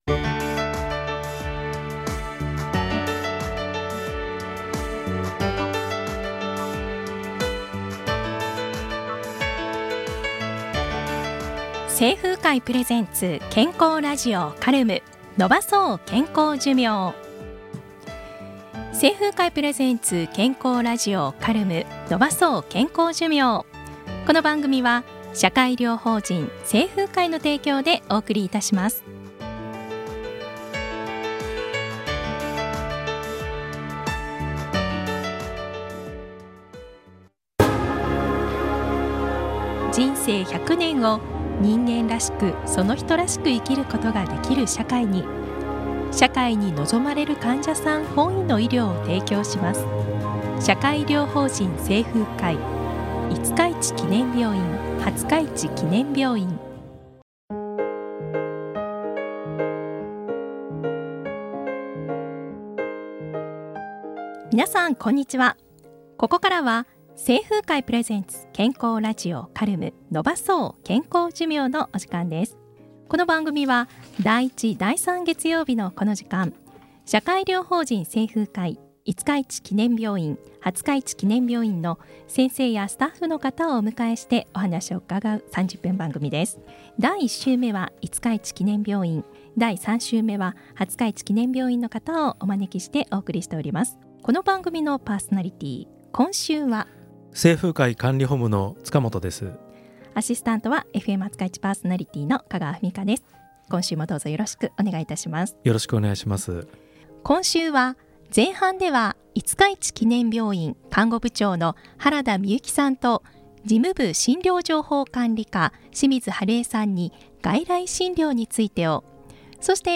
※著作権保護のため、楽曲は省略させて頂いています。